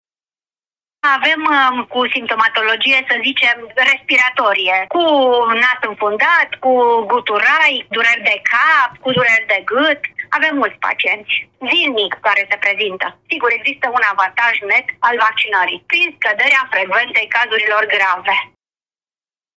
Medicul de familie